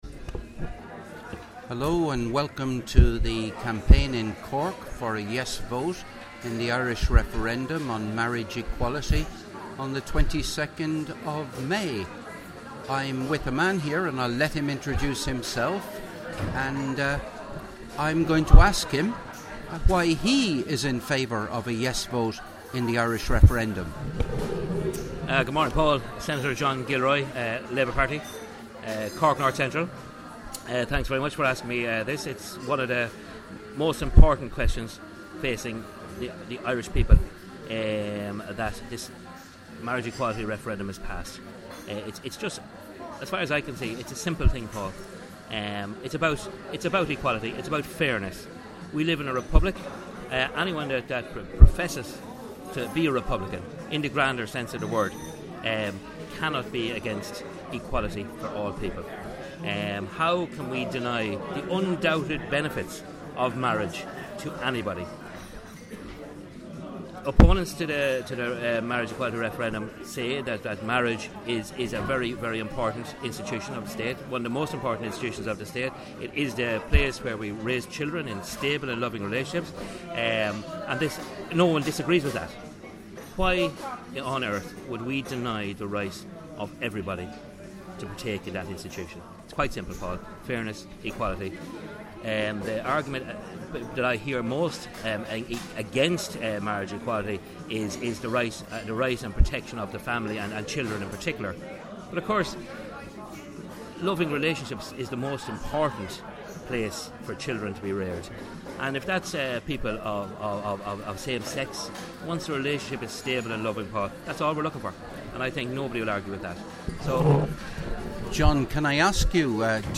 "Why I want a Yes vote" by Senator John Gilroy